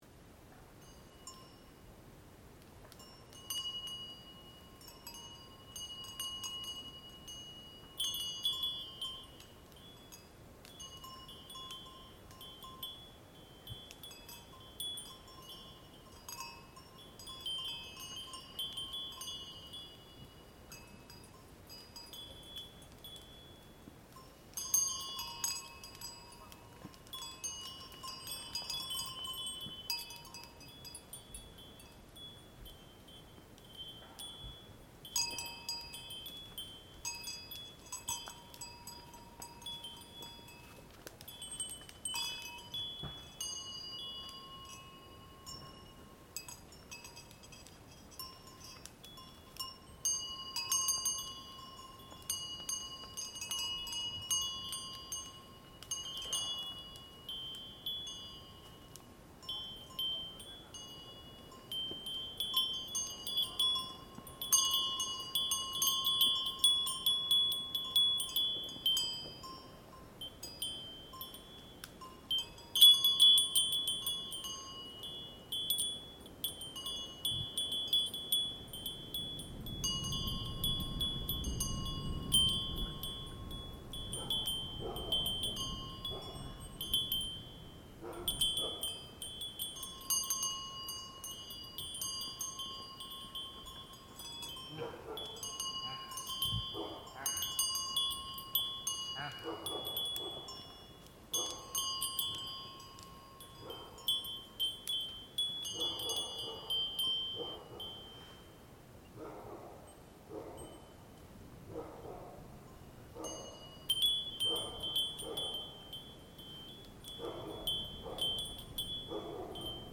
Spring reindeer bells in Khingan
So I took few days to keep the reindeer bells echo in the mountains.